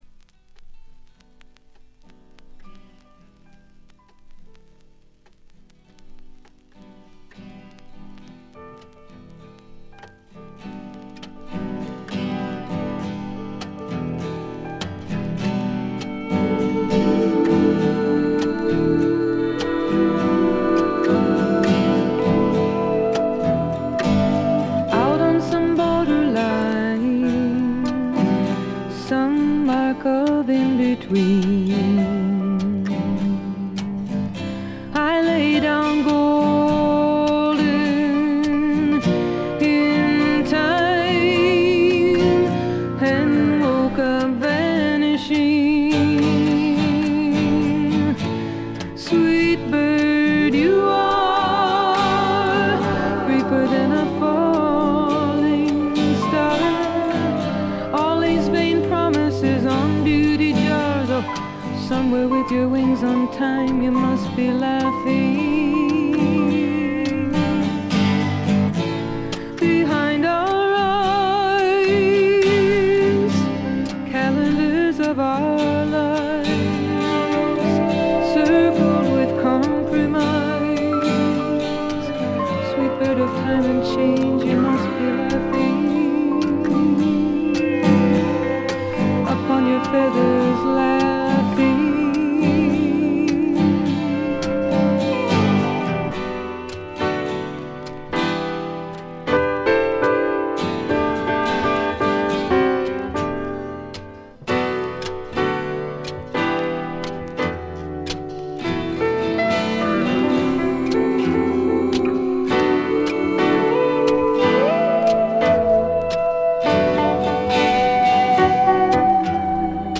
微細なバックグラウンドノイズ程度でほとんどノイズ感無し。
ここからが本格的なジャズ／フュージョン路線ということでフォーキーぽさは完全になくなりました。
試聴曲は現品からの取り込み音源です。